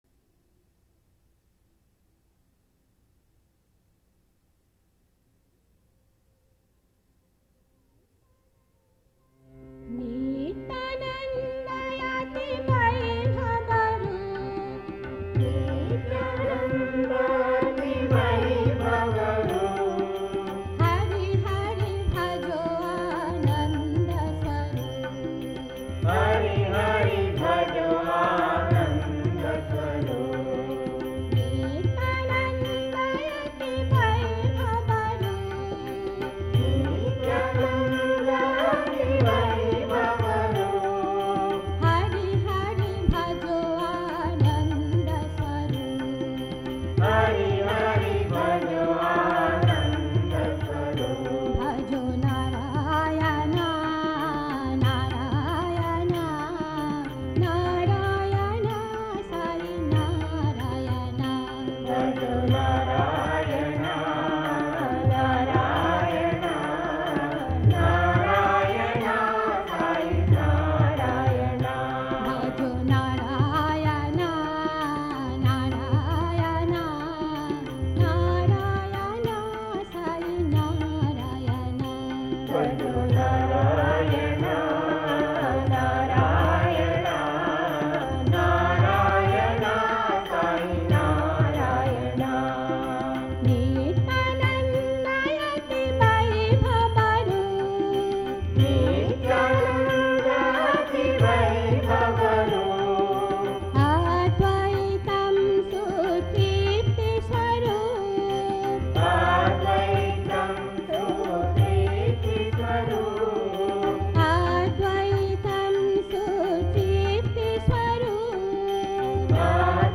1. Devotional Songs
Mishra Kapi 8 Beat  Men - 2.5 Pancham  Women - 6.5 Pancham
Mishra Kapi
8 Beat / Keherwa / Adi
2.5 Pancham / E Flat
6.5 Pancham / B Flat